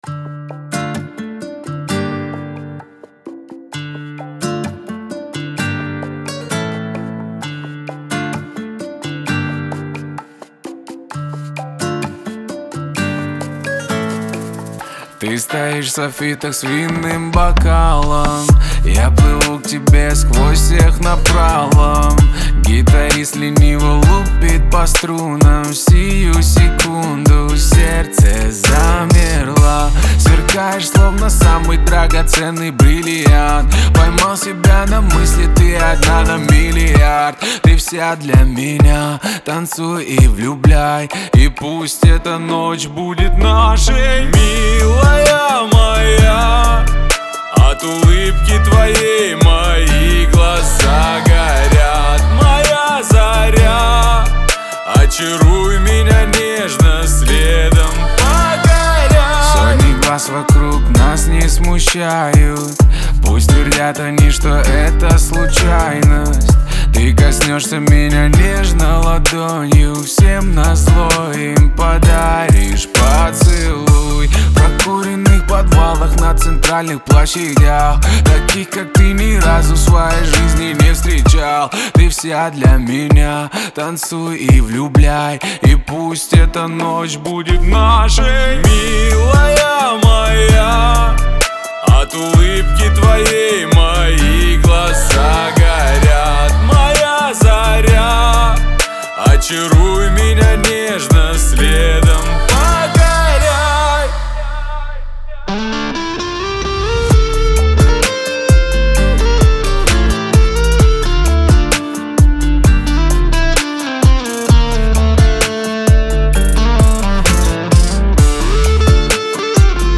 это яркий пример современного поп-рэпа